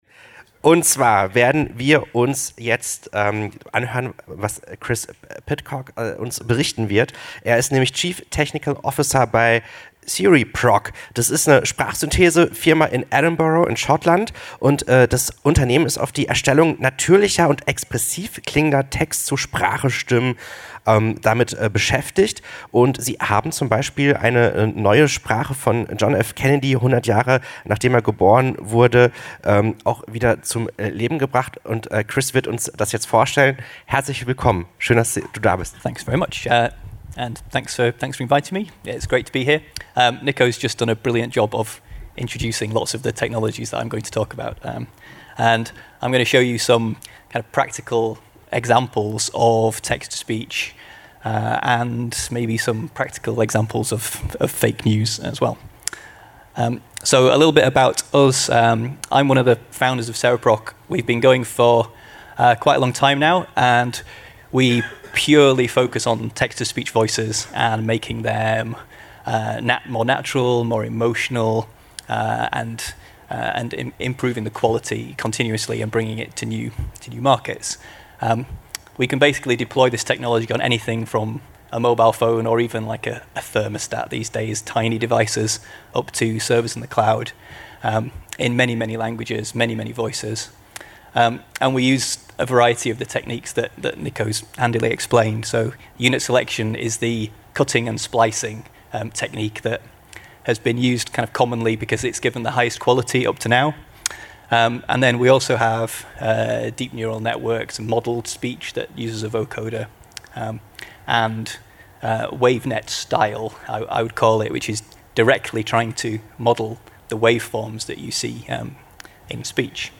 Vortrag über Stimmensynthese bei CereProc
München, ICM C6 Wann
Vgl.: Programm Medientage München